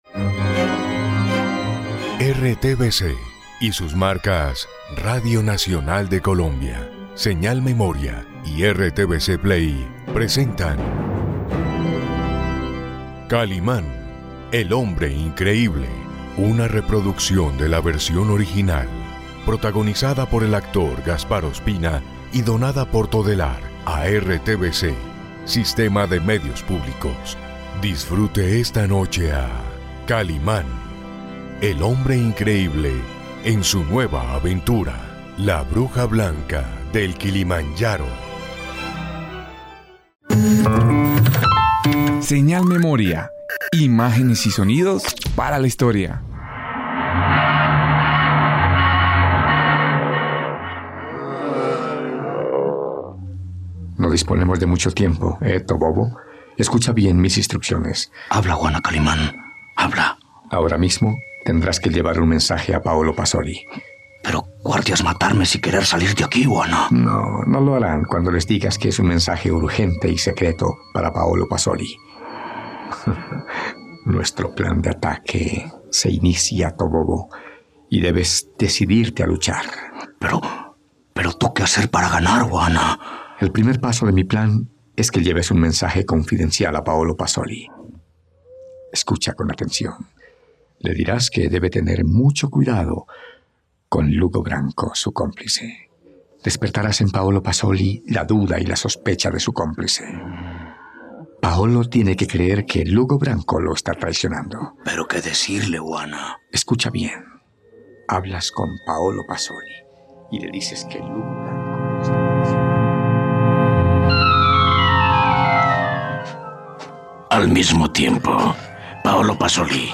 Encuentra esta radionovela solo en RTVCPlay.